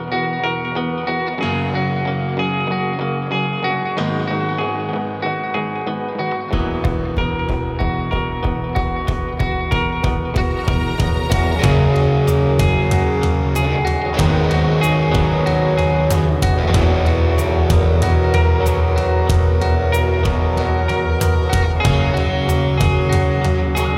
No Lead Guitar Pop (2000s) 4:56 Buy £1.50